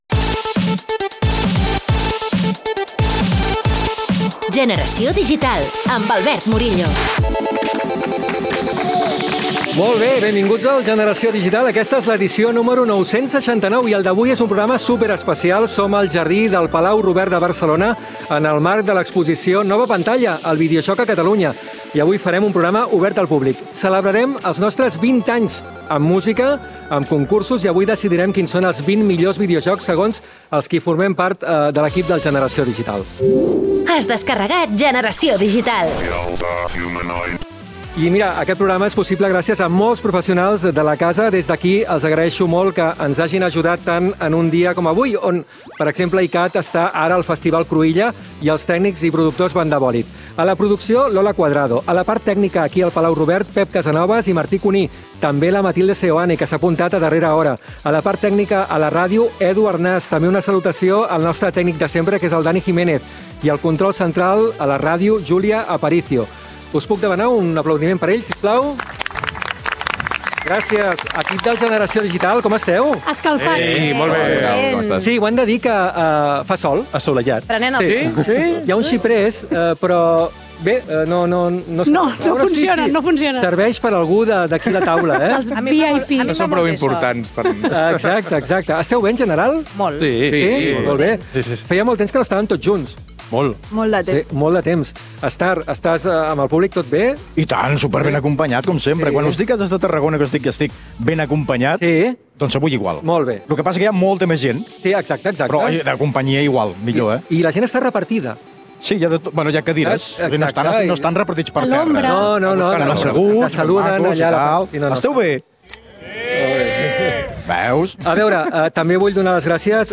Edició 969 feta des del Palau Robert de Catalunya. Salutació, equip del programa, què he après jugant a vídeojocs.
Divulgació
Programa on es celebren els vint anys en antena del Generació digital fet des del Palau Robert de Barcelona.